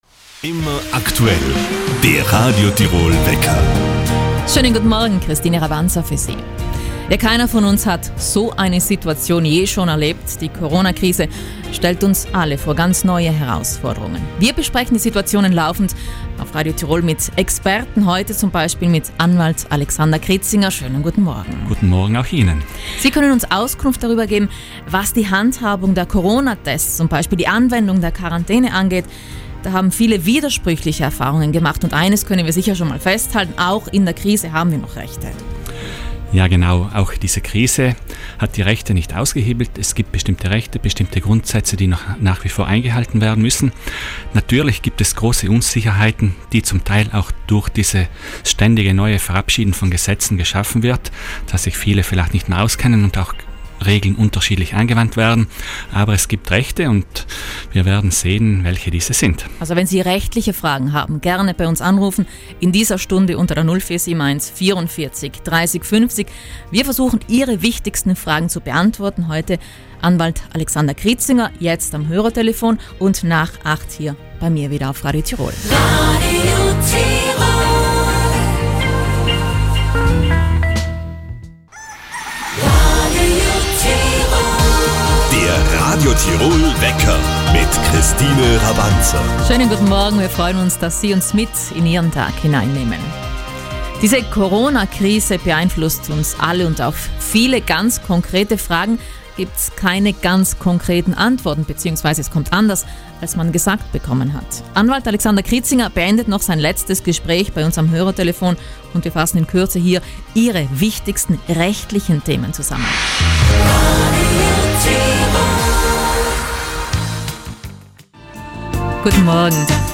am Hörertelefon